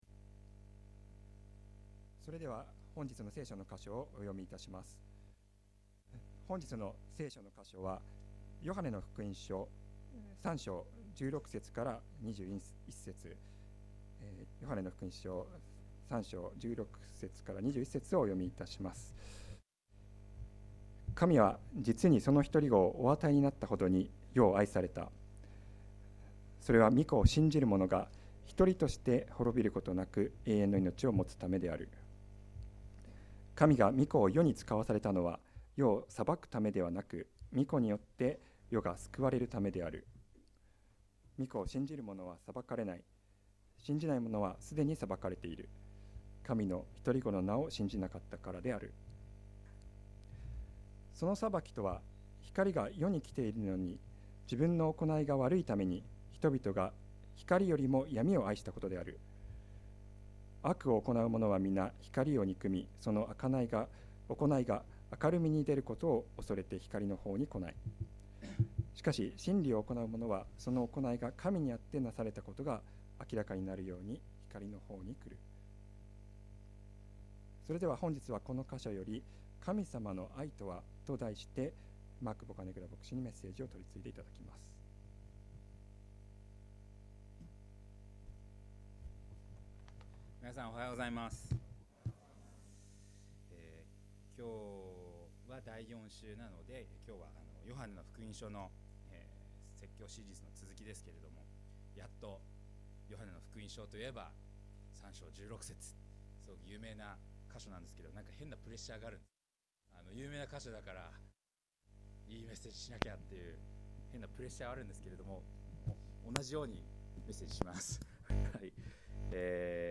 For English sermon summaries or other support to participate, please contact us.